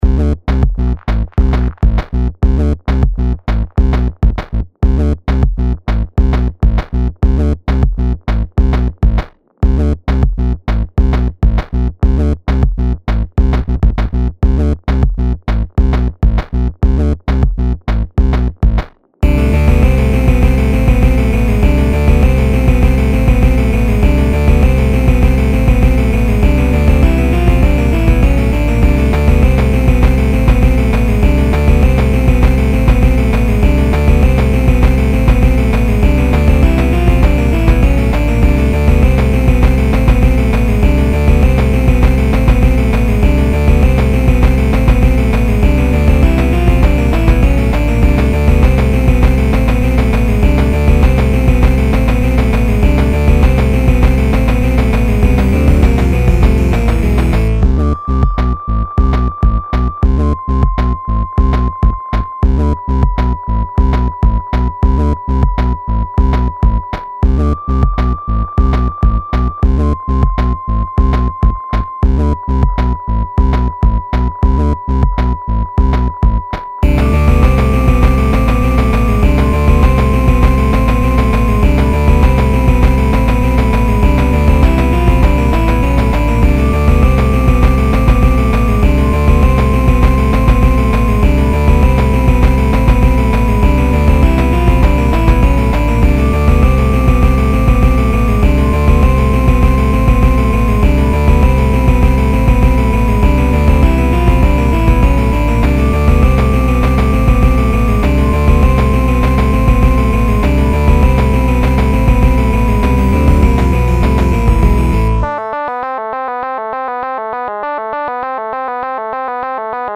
For now I bring you a beat that I made in Ableton.
I basically came up with theÂ harpsichordÂ sound and built from there.
Filed under: Instrumental | Comments (3)
I like the complexity of the beat with the bassline, good Job!
All good things start with harpsichord.